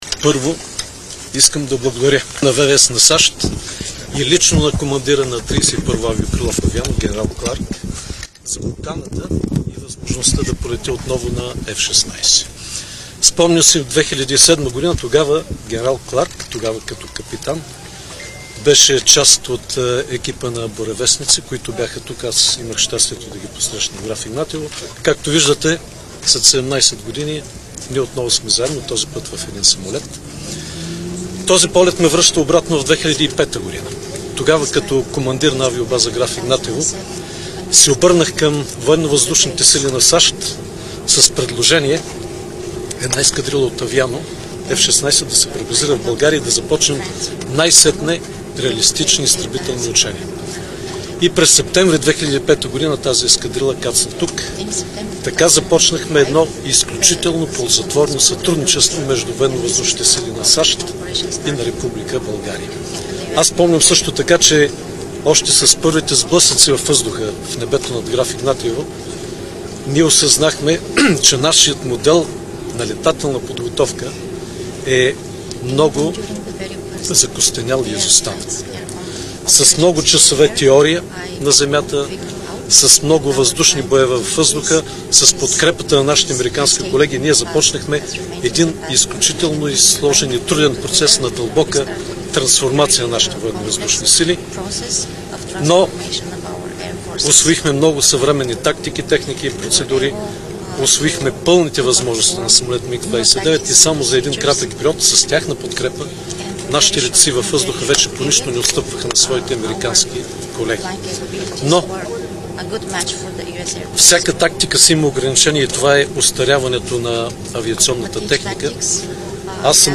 9.10 - Брифинг на съпредседателя на ПП Асен Василев за парите по ПВУ. - директно от мястото на събитието (Народното събрание)